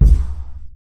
Bump.wav